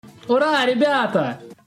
Мемный звук от популярного блогера Мазелова «Ура Роблокс».